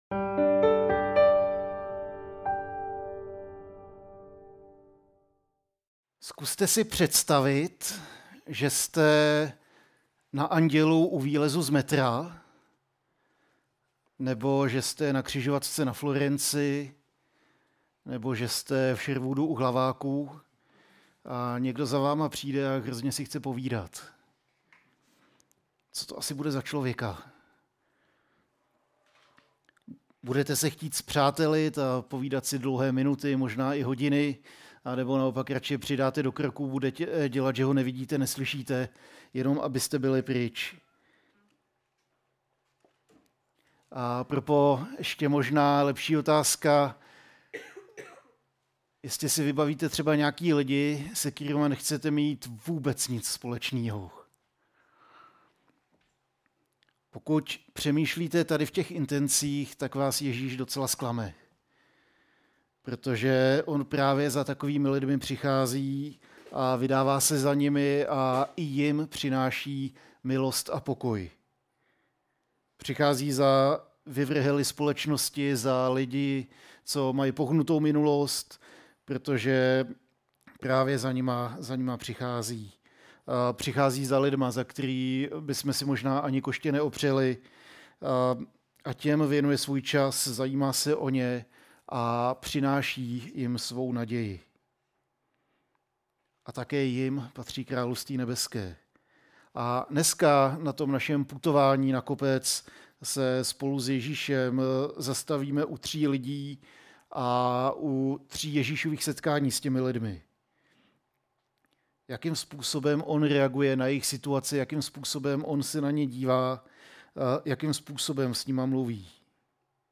Kázání | Pochodeň Praha